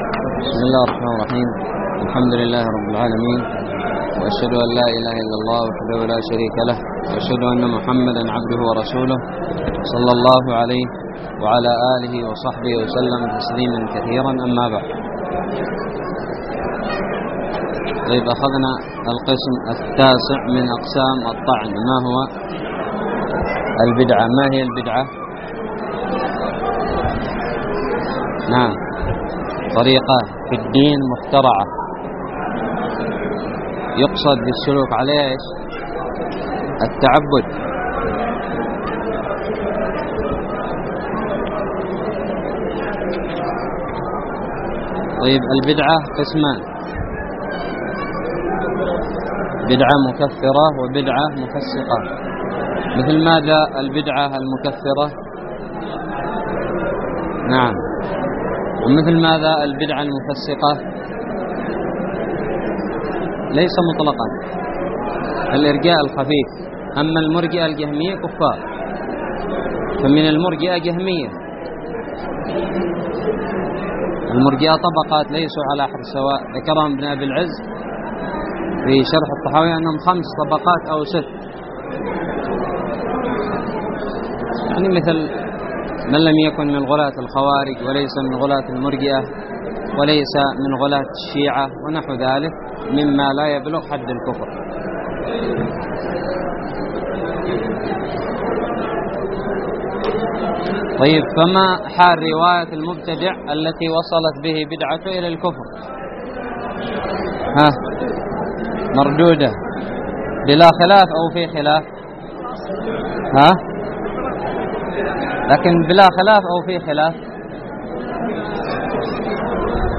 الدرس الثالث والثلاثون من شرح كتاب نزهة النظر
ألقيت بدار الحديث السلفية للعلوم الشرعية بالضالع